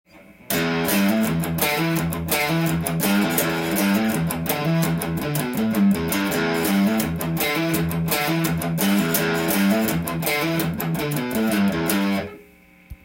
Emペンタトニックスケールで例を作ってみました
①のリフはエレキギターでよく使われる開放弦を多用したリフになります。
開放弦を使うことで低音弦を頻繁にしようし重低音を響かせることが出来ます。